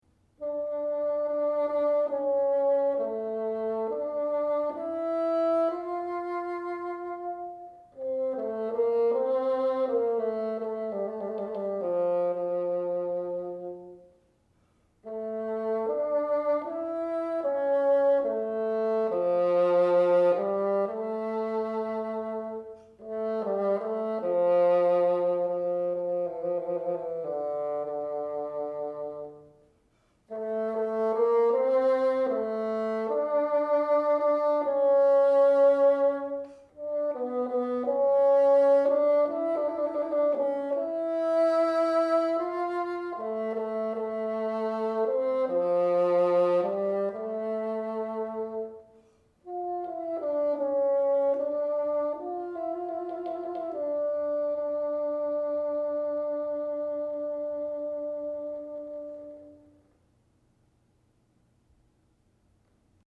Unit 47 | Music and the Bassoon
Subdivide eighth note triplets as you play the quarter note triplets.